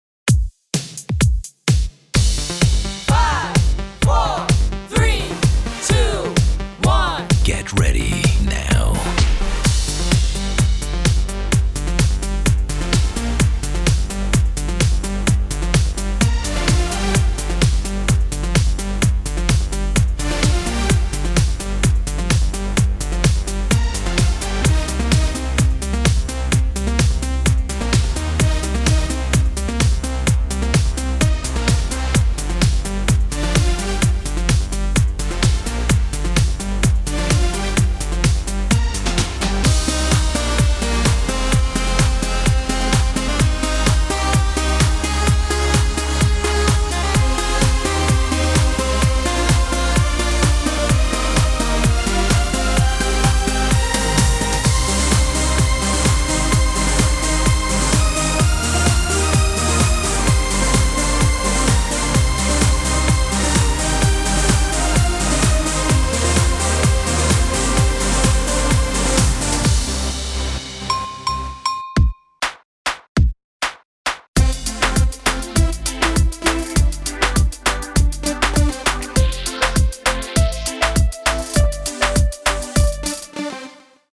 60 Sekunden aktiver Teil - 10 Sekunden Pause